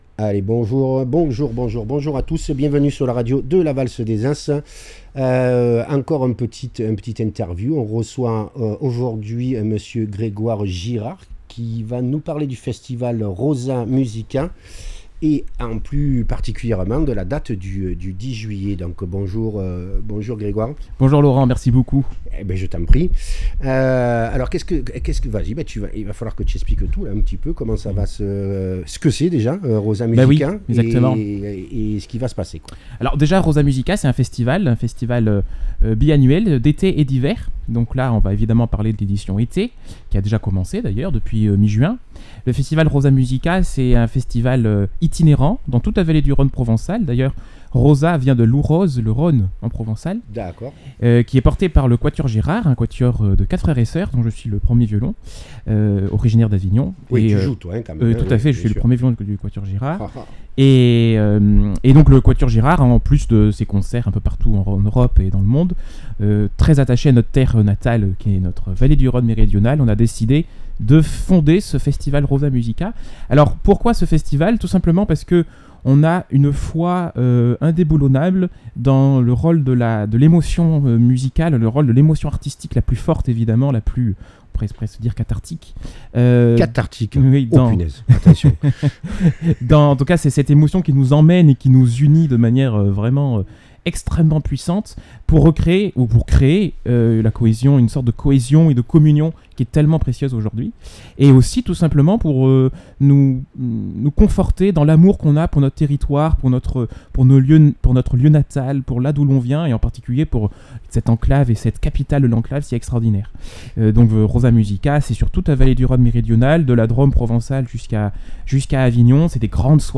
Venez decouvrir la musique classique .....